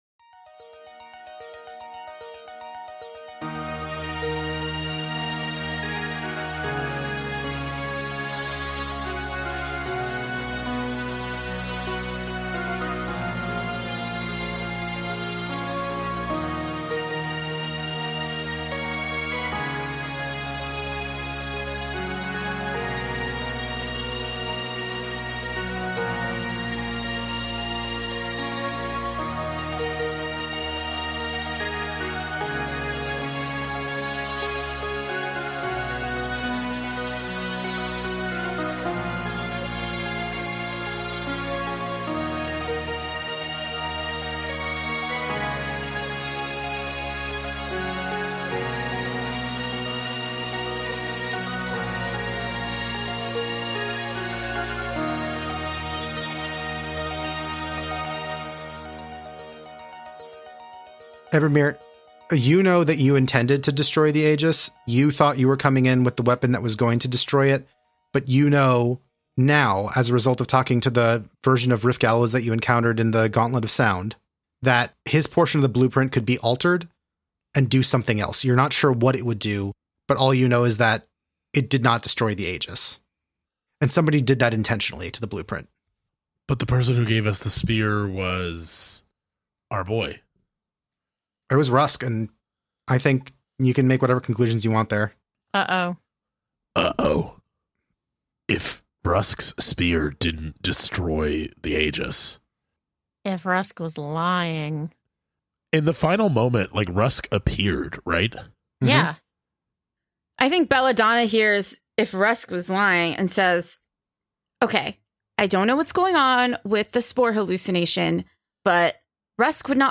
Worlds Away is an actual-play storytelling podcast where five close friends use games to create adventures together as a collective.